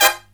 HIGH HIT09-L.wav